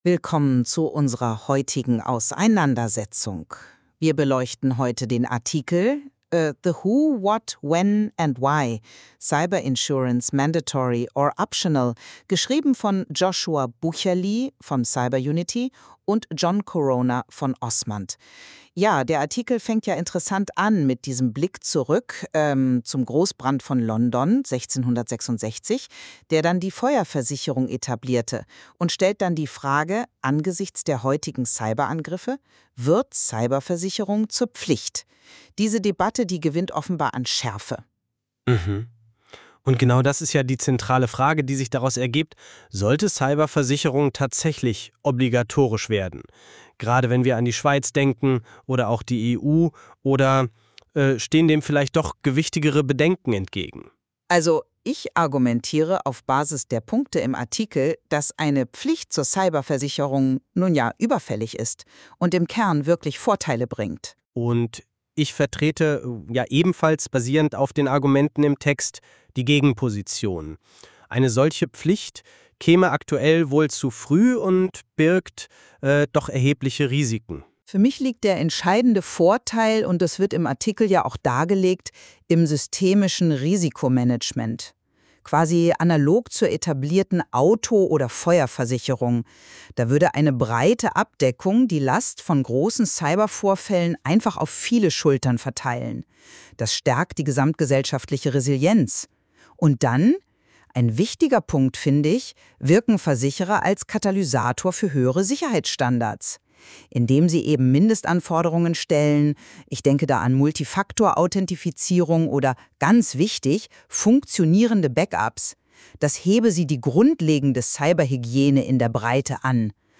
Podcast (DE): Cyber-Versicherung – Unterschiedliche Perspektiven (AI Narrated)